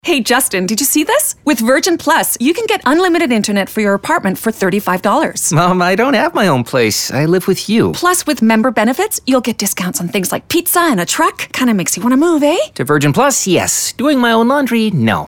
Publicité (Virgin) - ANG